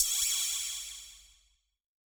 K-4 Flange Cymbal.wav